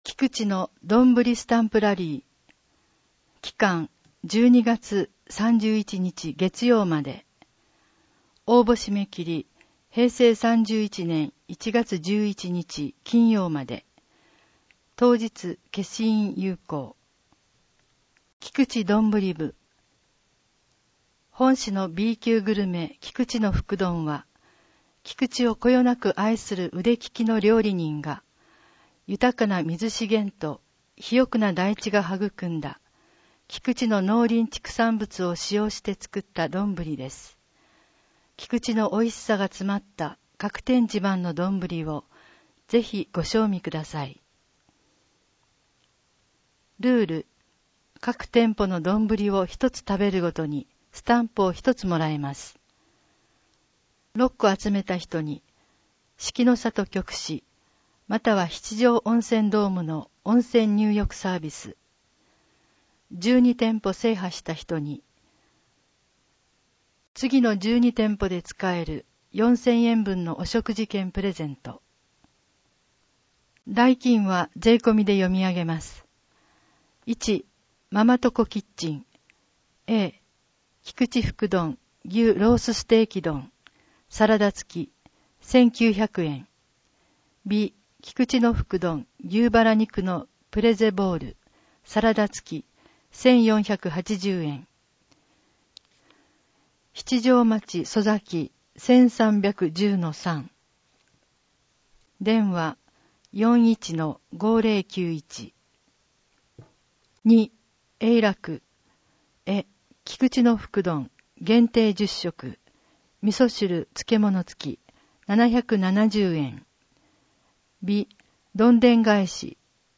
音訳
音訳は、「ひこばえ輪輪会」さんが行っています。